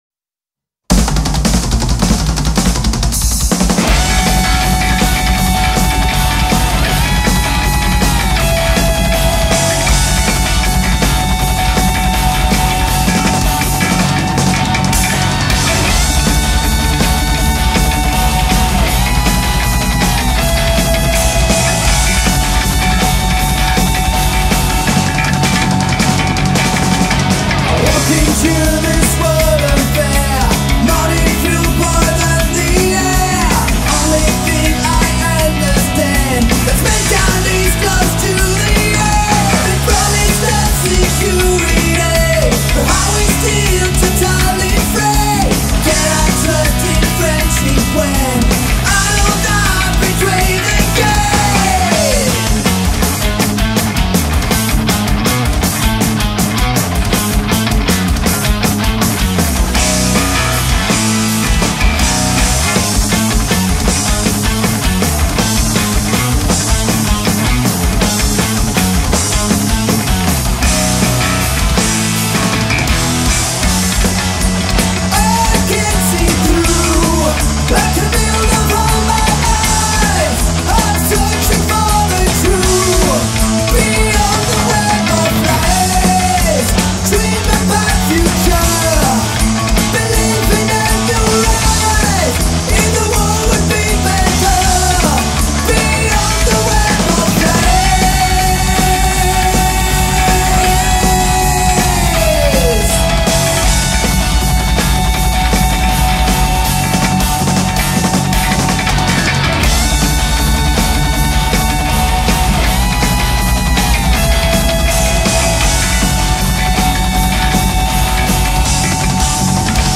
progressive metal quartet